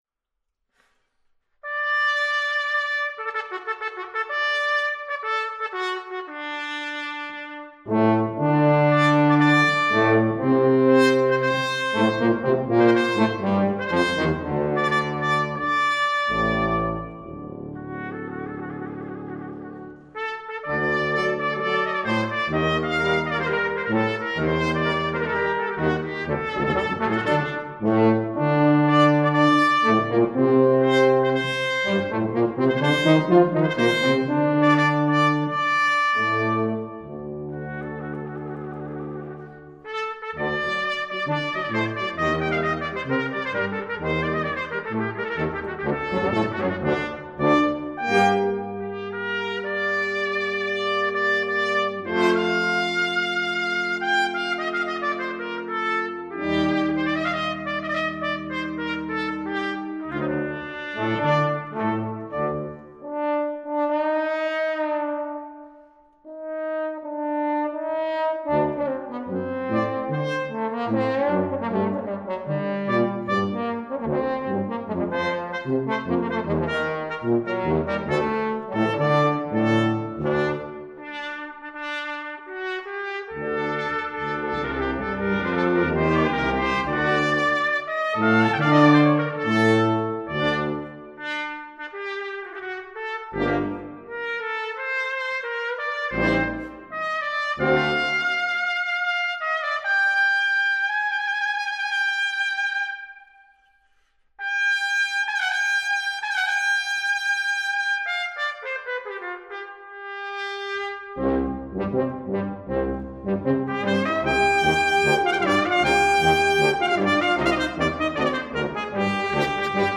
Posaune
Horn